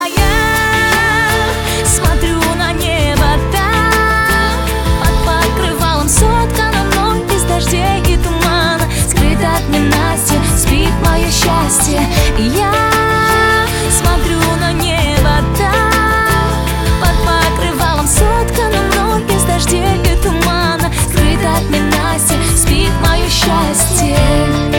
• Качество: 320, Stereo
красивые
женский вокал
мелодичные
приятные
колыбельные